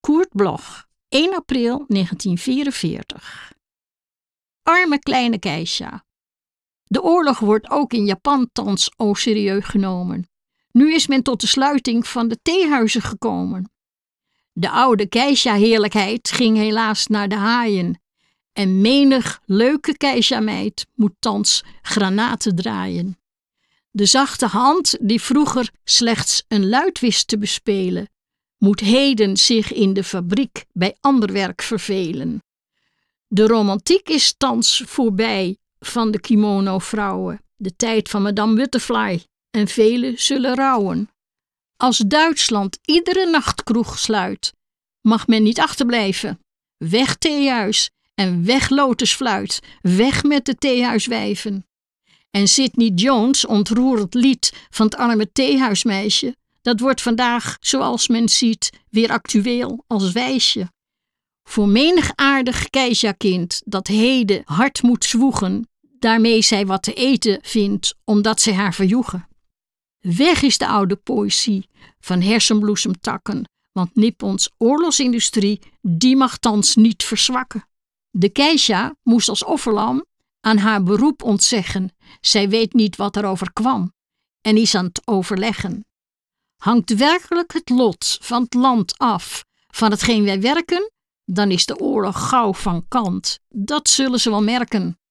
Recording: Studio Levalo, Amsterdam · Editing: Kristen & Schmidt, Wiesbaden